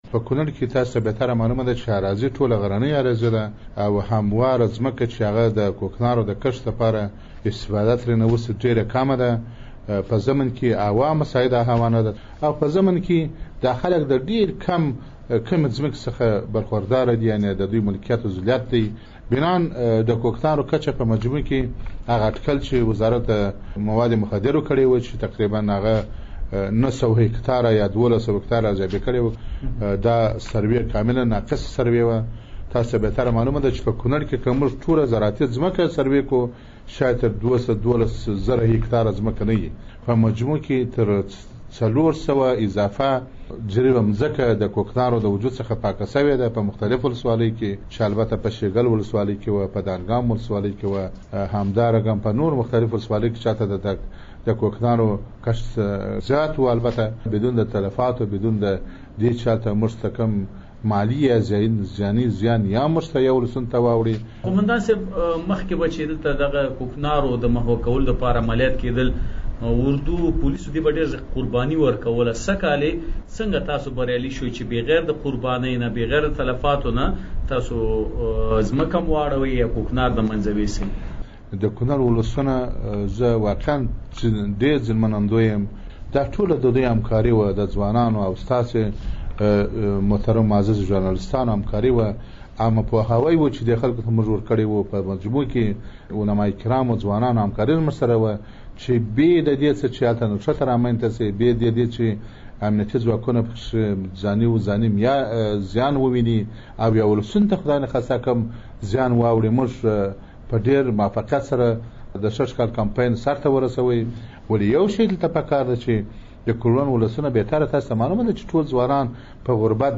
د کونړ له امنیه قوماندان جمعه ګل همت سره مرکه